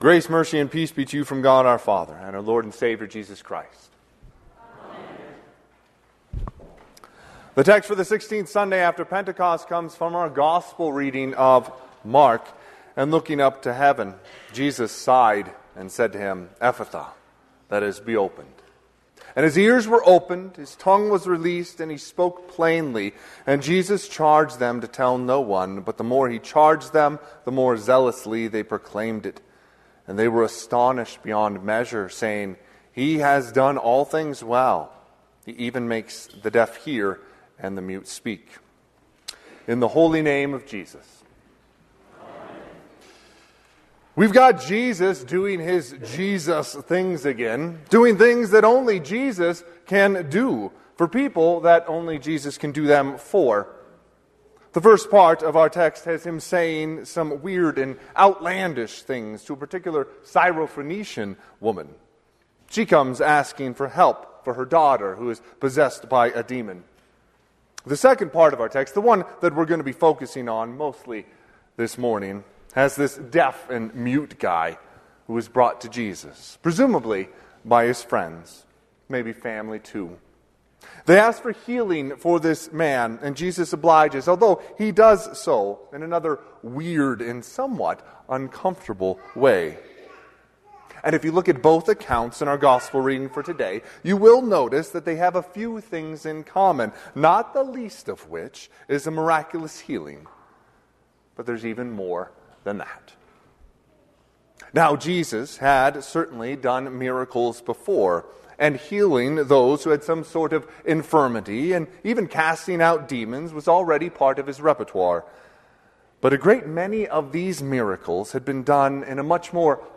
Sermon - 9/8/2024 - Wheat Ridge Lutheran Church, Wheat Ridge, Colorado
Sixteenth Sunday after Pentecost
Sermon_Sept8_2024.mp3